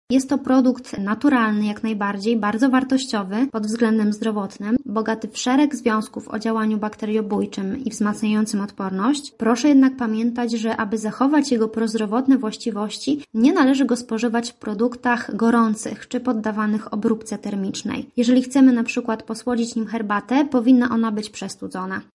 Za nami II Wojewódzkie Święto Miodu.